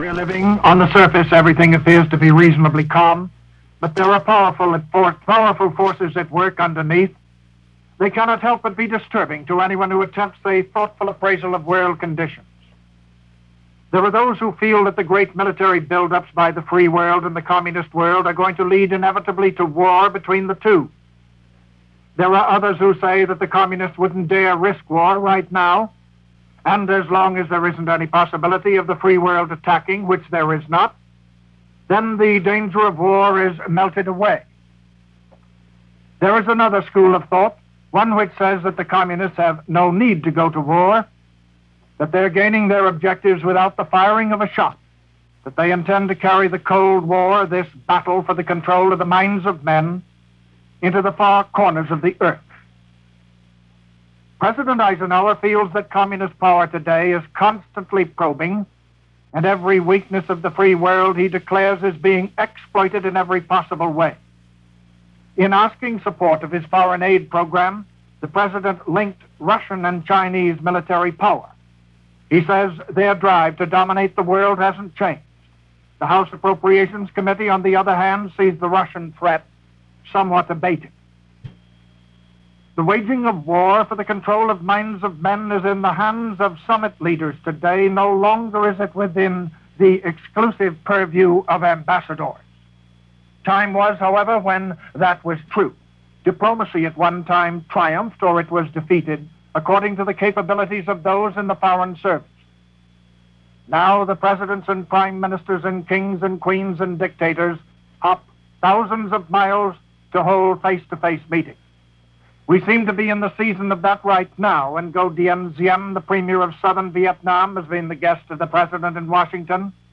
To get an idea, or some flavor of the anxieties we experienced those decades past, here is a commentary by one of the leading broadcast journalists of the day, Cedric Foster to offer some insights and thoughts on this Cold War world of 1957.
From the Mutual Radio network, Cedric Foster, from May 1957.
MBS-Cedric-Foster-May-1957.mp3